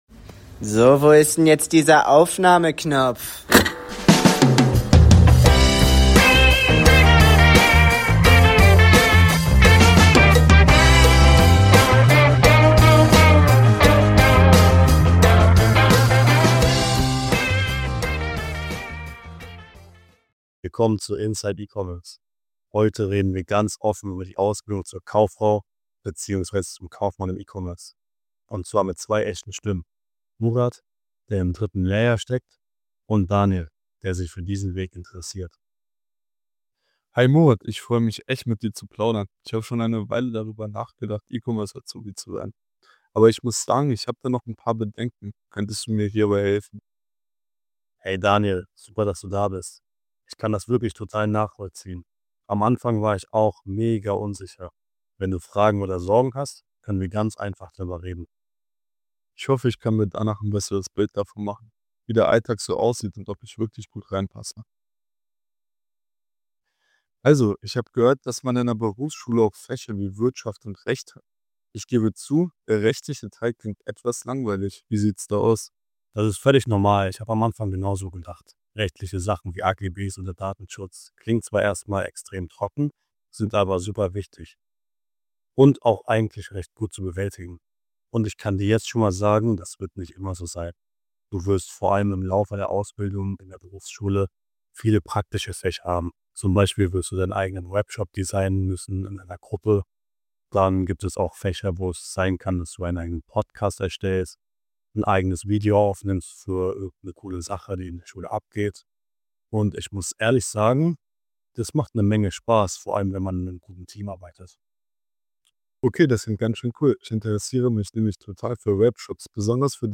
• 00:00 Intro-Jingle